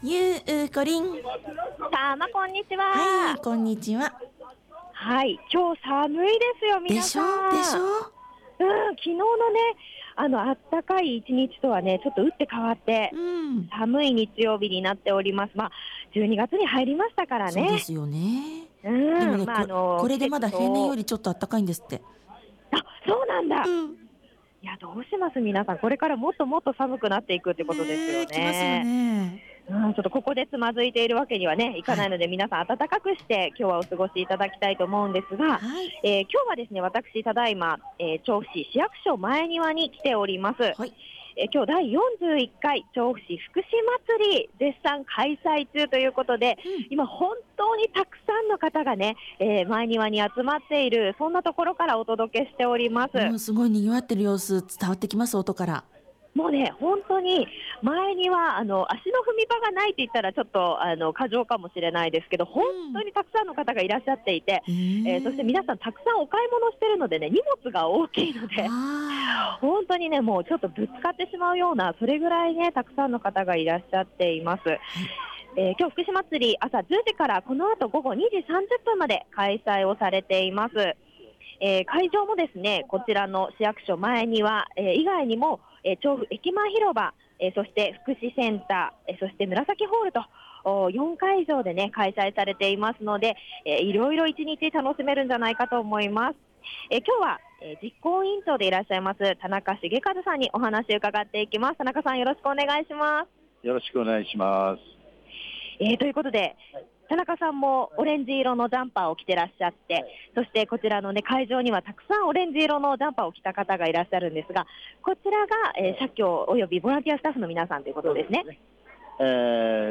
第41回調布市福祉まつりの市役所前庭広場会場からレポートお届けしました。